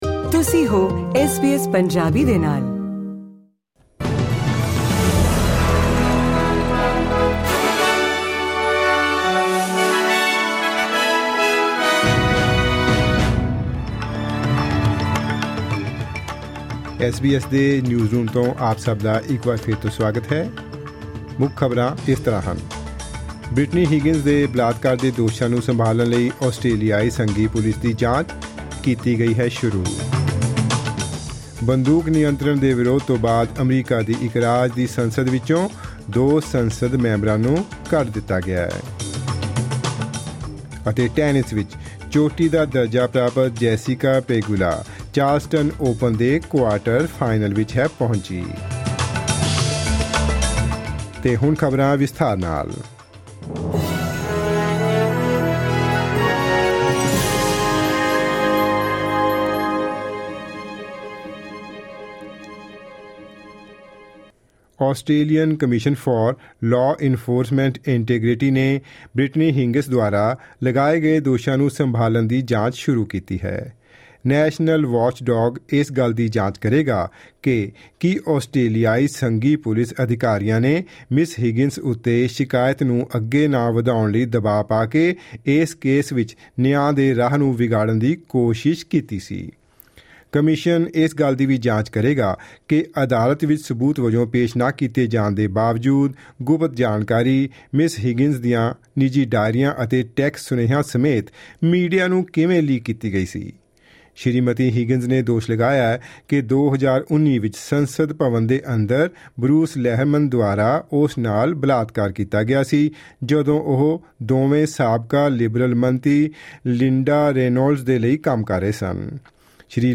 SBS Punjabi Australia News: Friday 7 April 2023